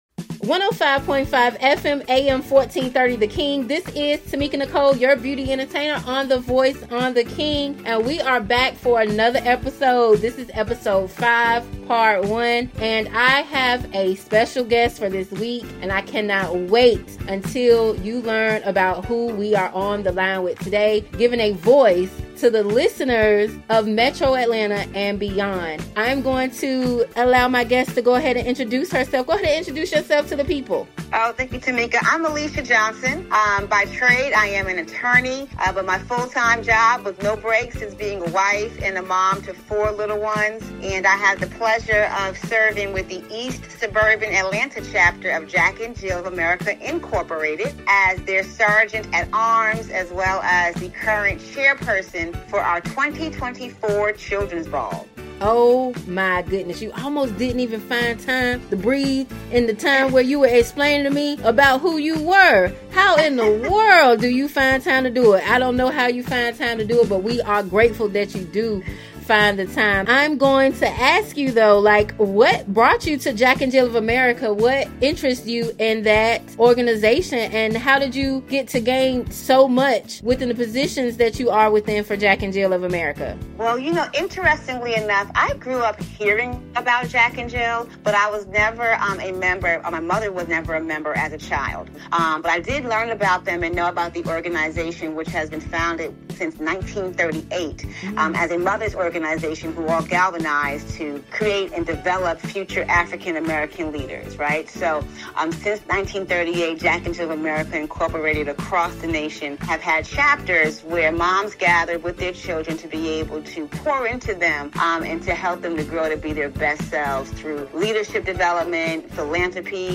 The Voice is a 2 Part Segment Show where local and national leaders share their stories with the world!
Heard on 105.5 FM/AM 1430 & AM 1010 The King